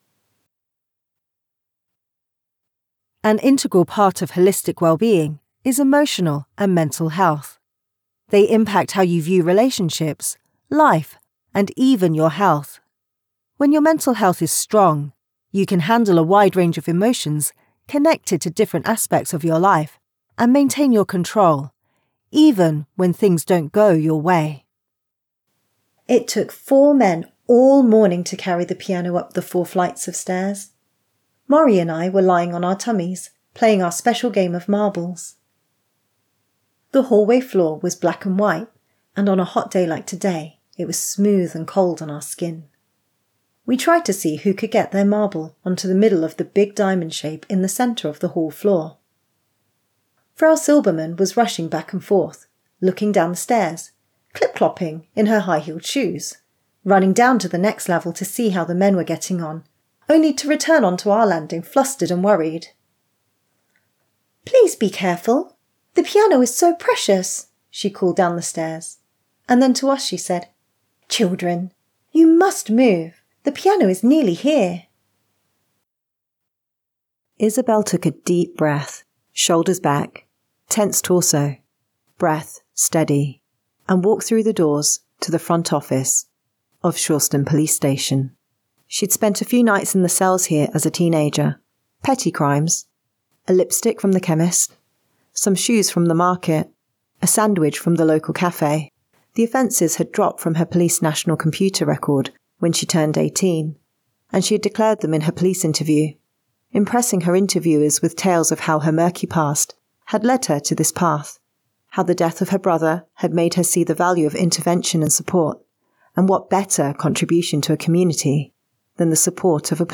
Professional Storyteller
Commercial Demo
British RP
Middle Aged